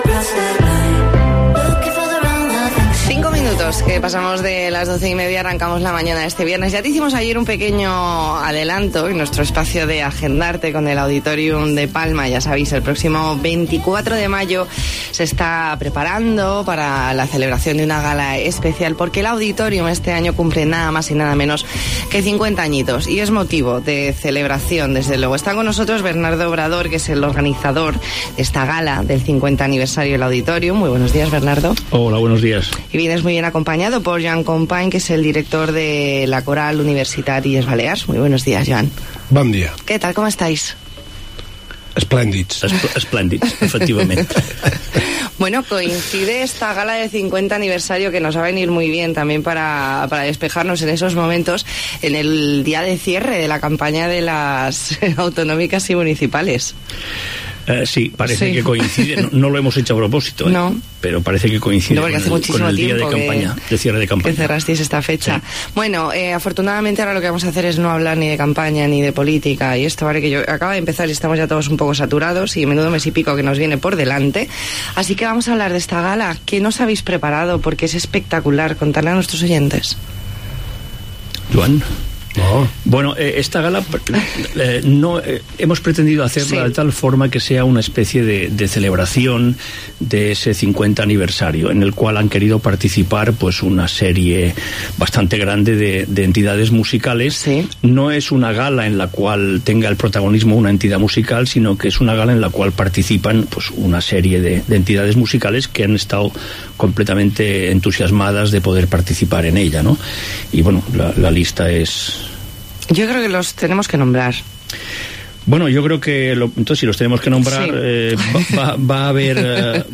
Entrevista en La Mañana en COPE Más Mallorca, viernes 12 de abril de 2019.